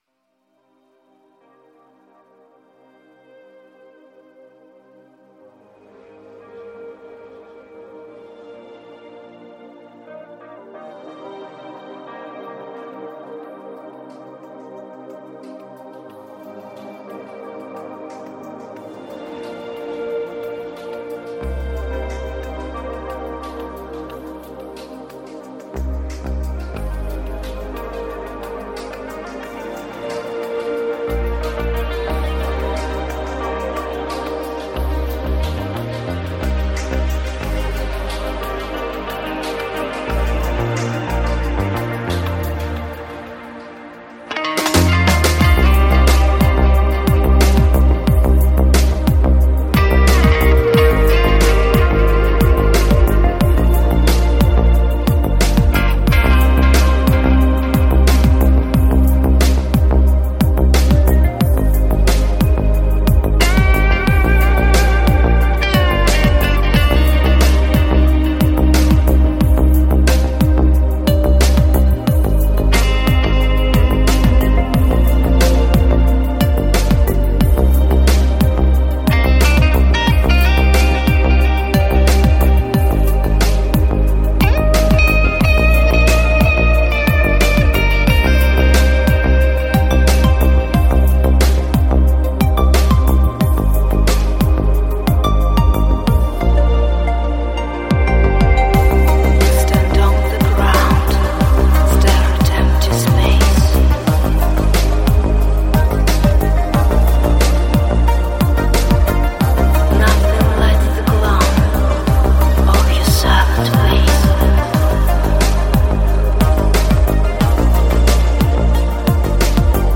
Жанр: Dance
Транс, Дискотека 90 - 2000-ые